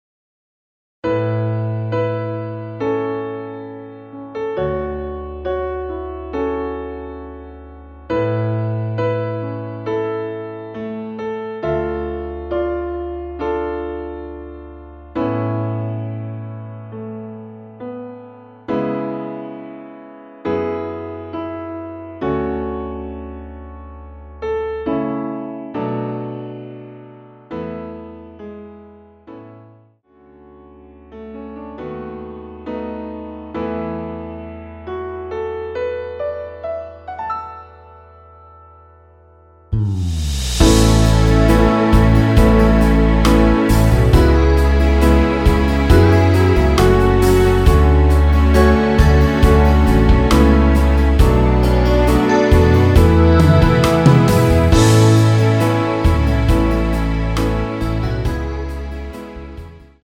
라이브용 버전2절 없이 진행 됩니다.(아래 동영상및 가사 참조)
앞부분30초, 뒷부분30초씩 편집해서 올려 드리고 있습니다.
중간에 음이 끈어지고 다시 나오는 이유는